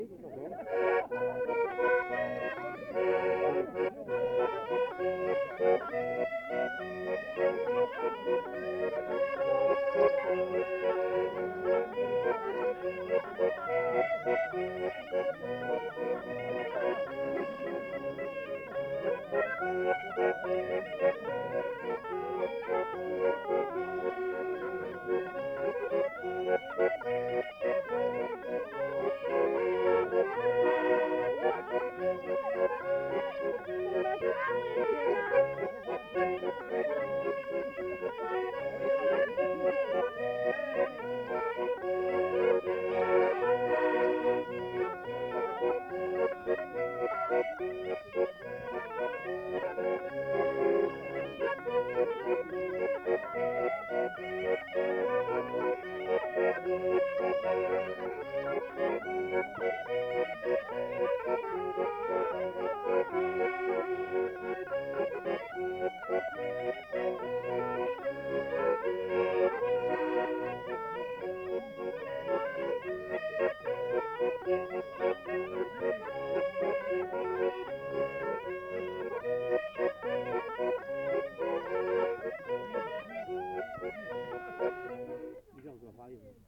Aire culturelle : Viadène
Genre : morceau instrumental
Instrument de musique : cabrette ; accordéon chromatique
Danse : valse